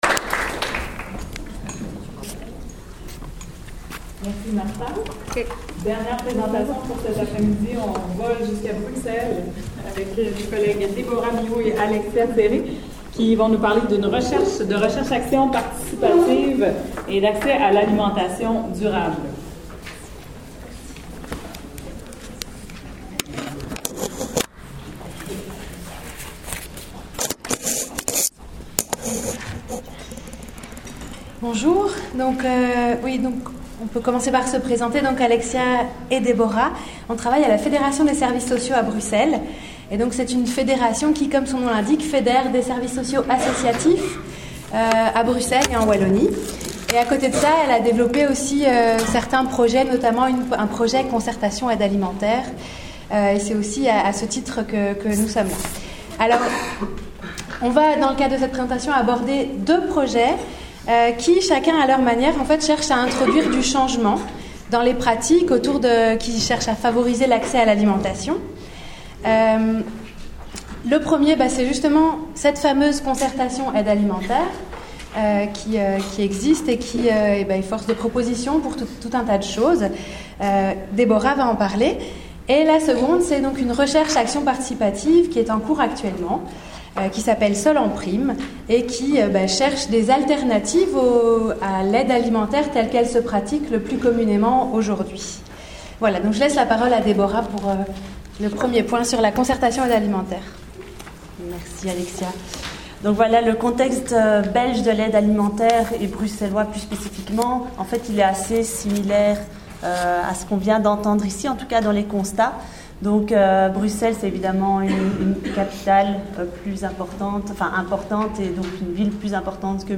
Ce documentaire sonore est le fruit d’une collaboration entre le GSARA et le partenariat Solenprim. Il permet de découvrir trois des quatre projets locaux lancés dans le cadre de Solenprim, les participants à ces initiatives et quelques apprentissages de la recherche-action.